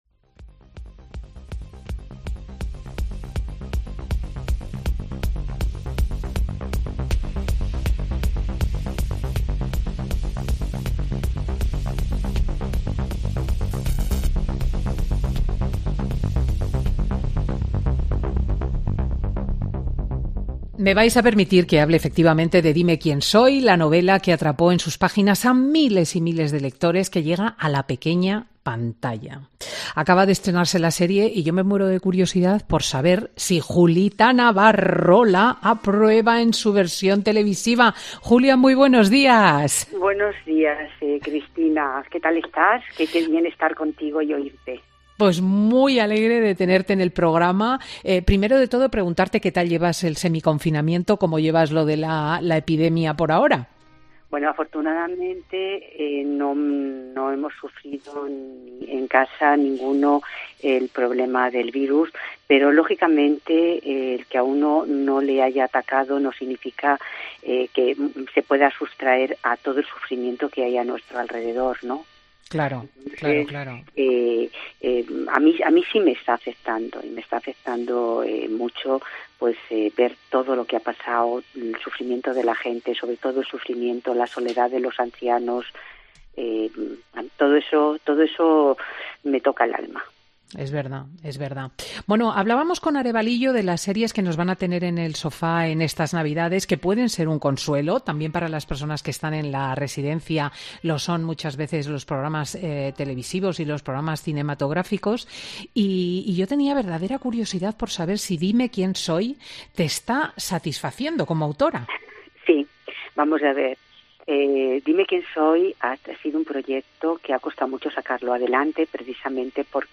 AUDIO: La escritora comenta en Fin de Semana con Cristina que ha necesitado cuatro equipos de guionistas hasta que ha quedado contenta con el...